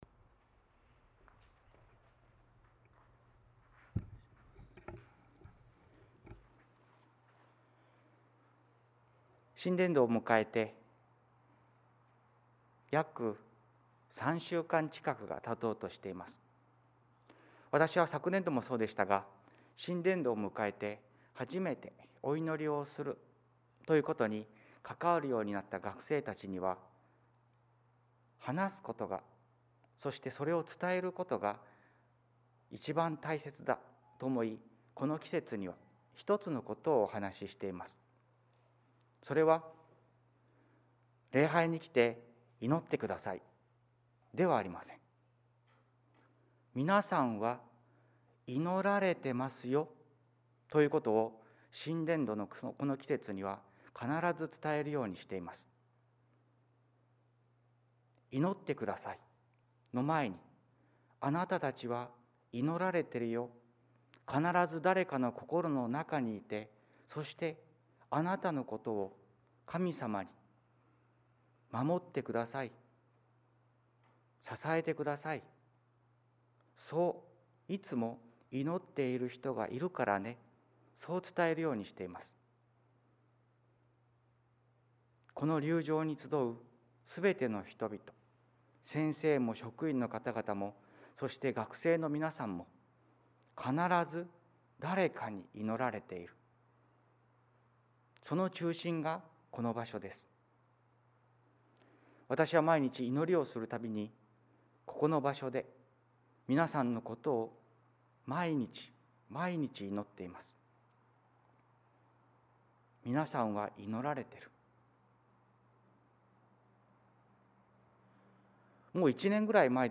終業礼拝（保育科1年生）2021/3/5